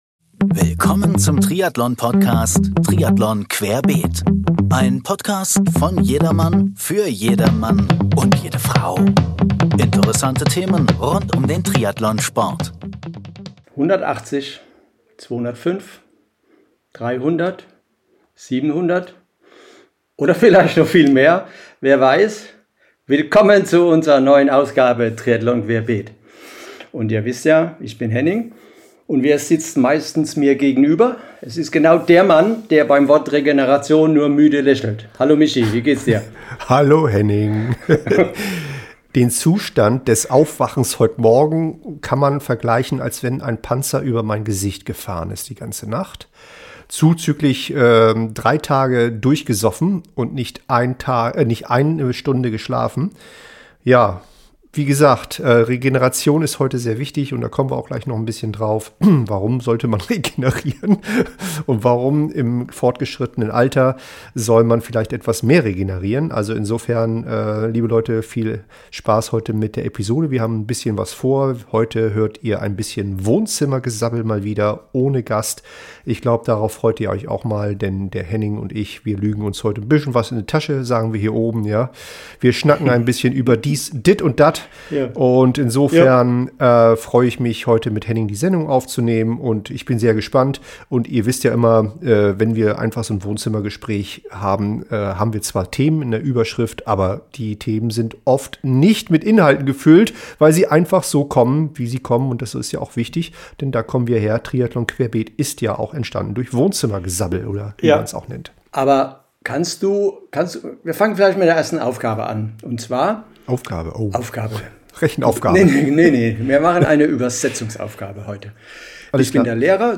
Beschreibung vor 9 Monaten Endlich wieder eine echte Wohnzimmerfolge!